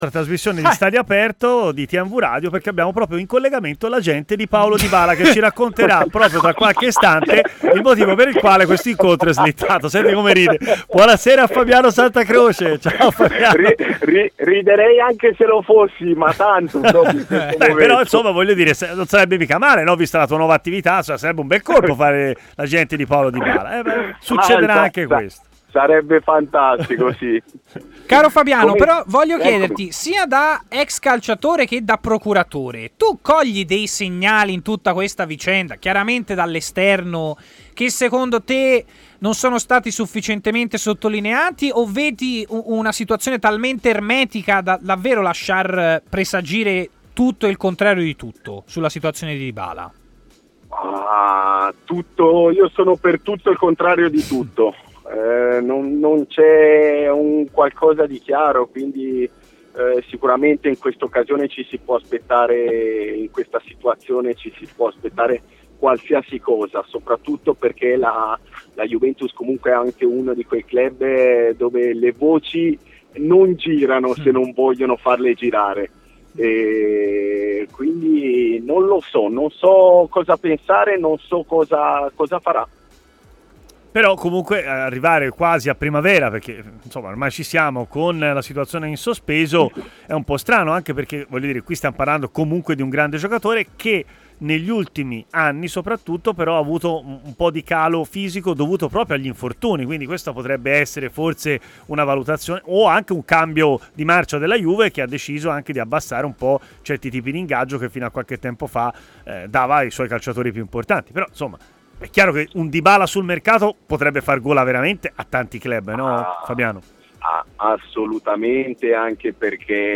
Fabiano Santacroce ha parlato a Stadio Aperto su TMW Radio: